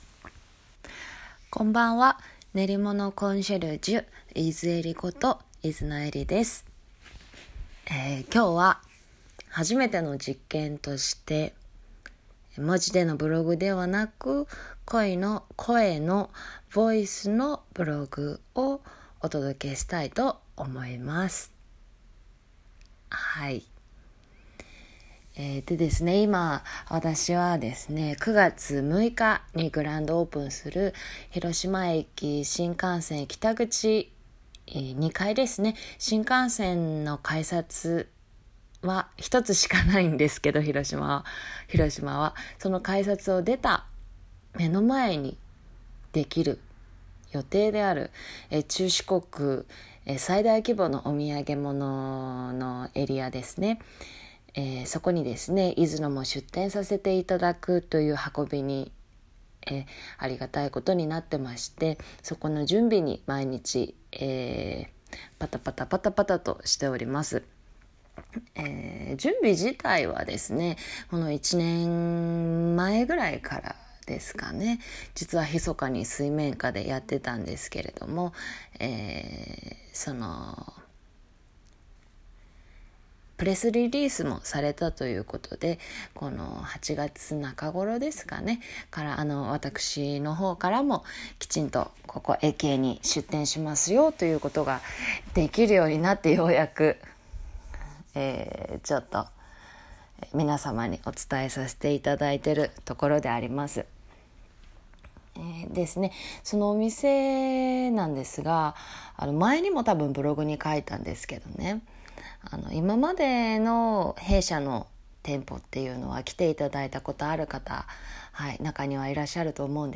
今日は初めての実験ブログ！ ということで 音声でヴォイスブログを撮ってみました
実験！今日はヴォイスブログです